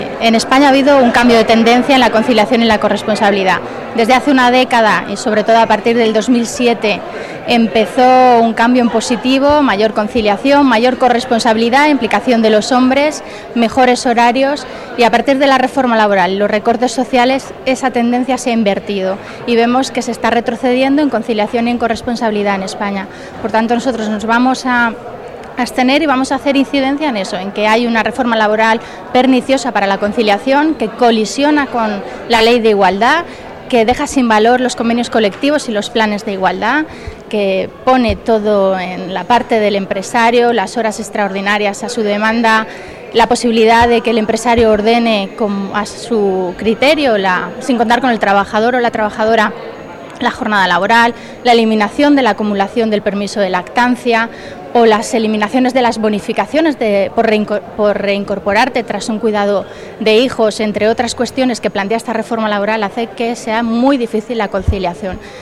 Declaraciones de Carmen Montón en el Congreso sobre el retroceso en España en materia de conciliación laboral y familiar desde la entrada en vigor de la reforma laboral del PP 26/09/2013